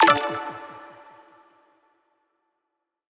button-direct-select.wav